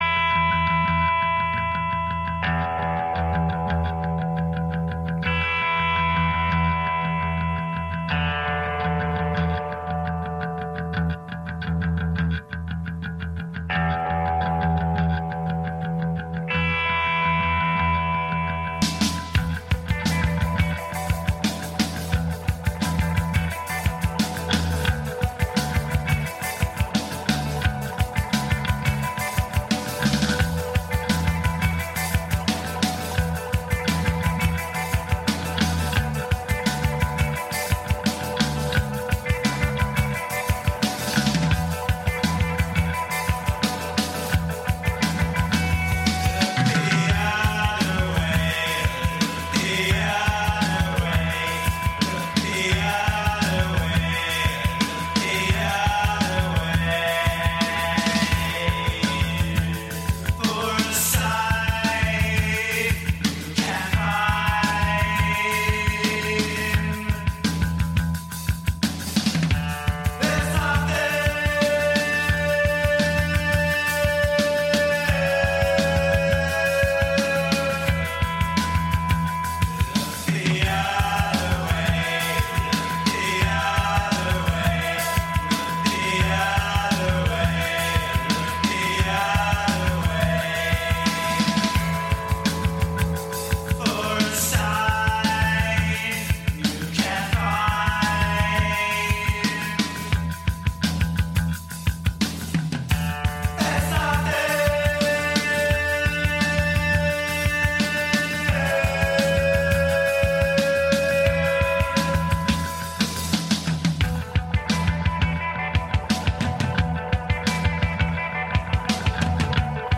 A little Russian Punk, a little Western Swing and everything inbetween.